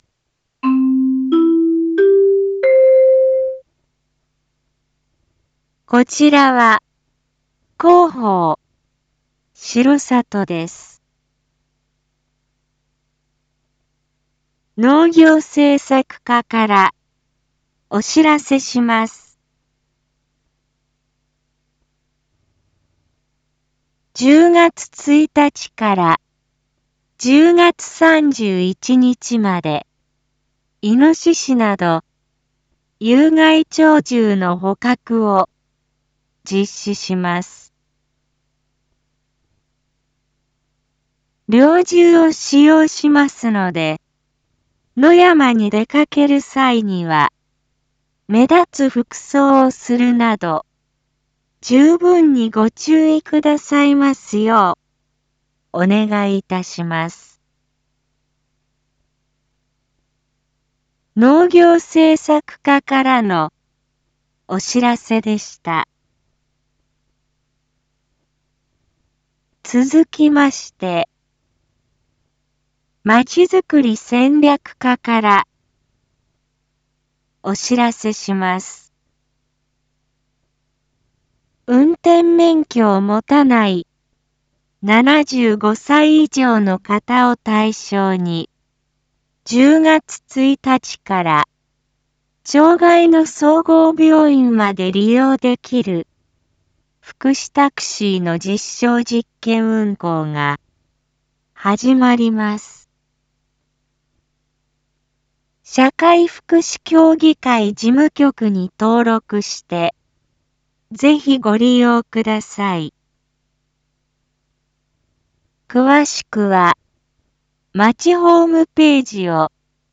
Back Home 一般放送情報 音声放送 再生 一般放送情報 登録日時：2024-09-28 19:02:34 タイトル：①有害鳥獣捕獲について インフォメーション：こちらは、広報しろさとです。